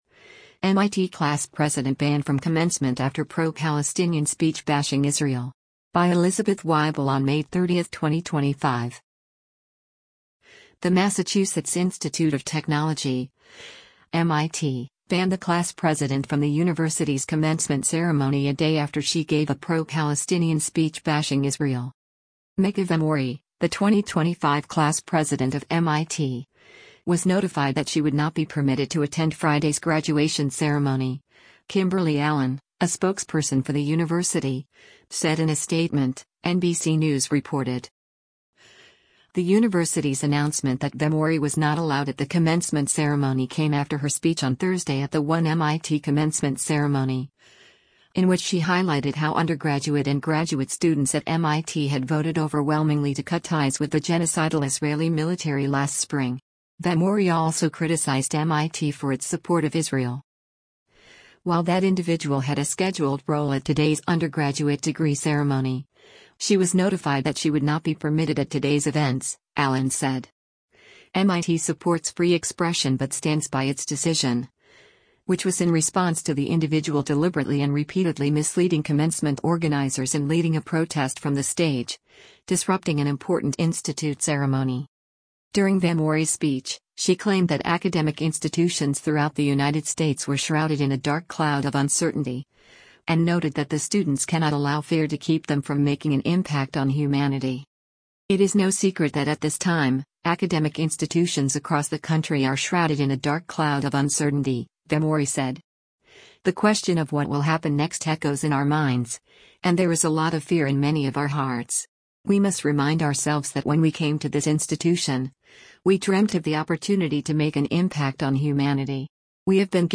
people in the crowd could be heard cheering